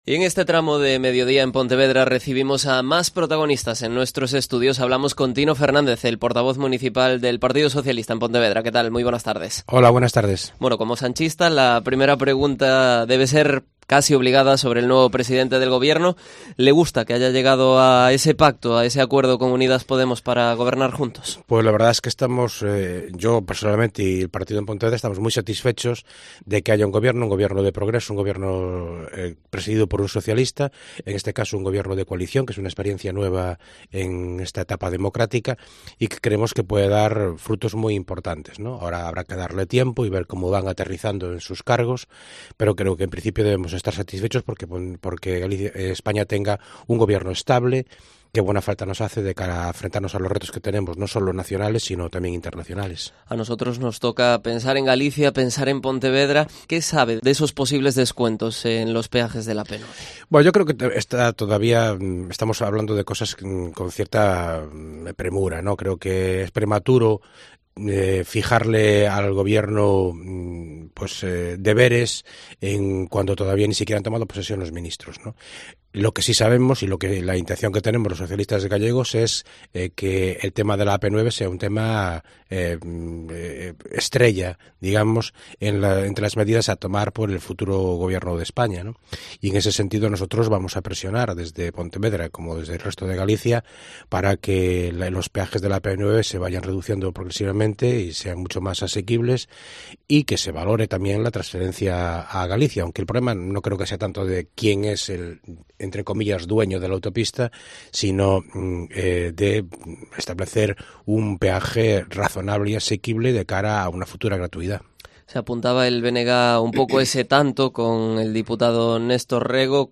Entrevista de Tino Fernández, teniente de alcalde de Pontevedra y secretario de organización del PSOE local